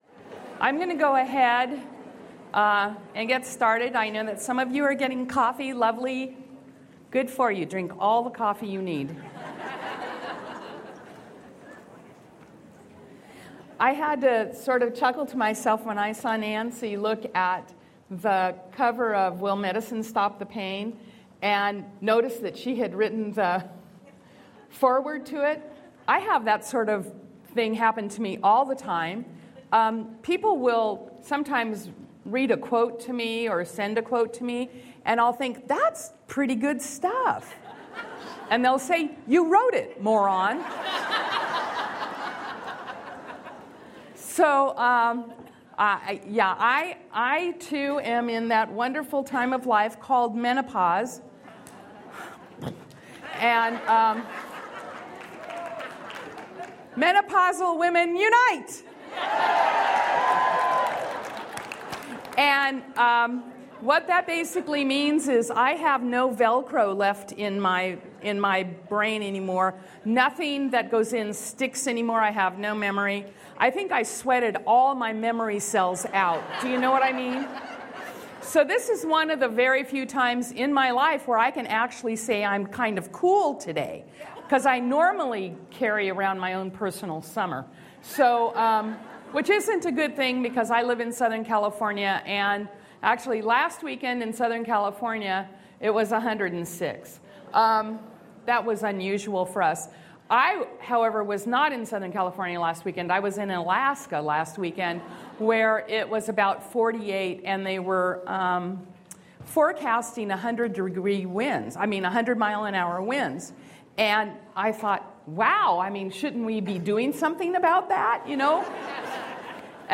Leadership Session: Women Helping Women, In Him All the Treasures, Part 1